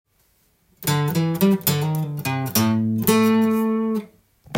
Aのブルースでも弾けるようにkeyを変換してみました.
オリジナルフレーズはkeyがEでしたがAのブルースで弾けるように